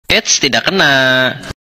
Efek Suara Meme Eits Tidak Kena
Kategori: Suara viral
Keterangan: Eits Tidak Kena Meme Sund Effect lucu Indonesia ini viral digunakan dalam edit video.
efek-suara-meme-eits-tidak-kena-id-www_tiengdong_com.mp3